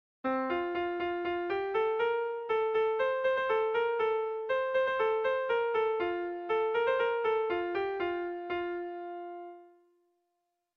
Sehaskakoa
ABDE